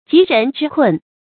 注音：ㄐㄧˊ ㄖㄣˊ ㄓㄧ ㄎㄨㄣˋ
急人之困的讀法